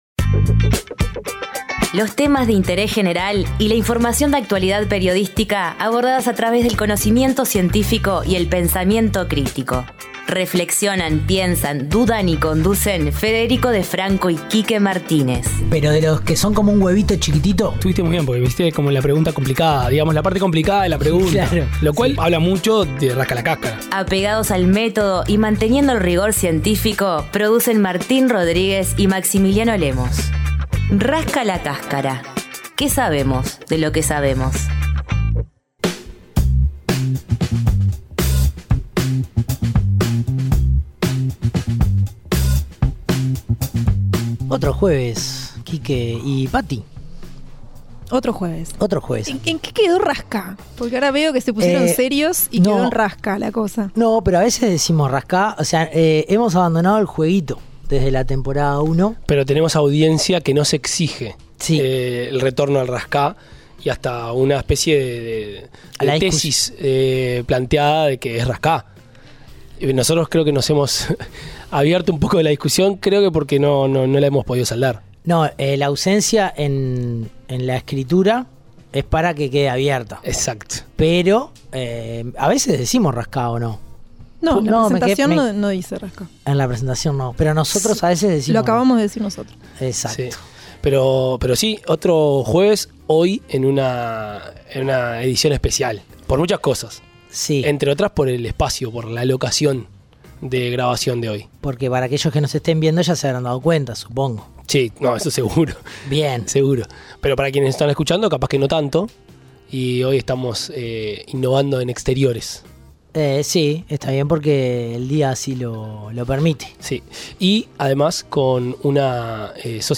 La charla se dio en un lugar distinto, en otro contexto, y surgieron preguntas cómo: ¿Qué tiene de distinto el fútbol entre mujeres?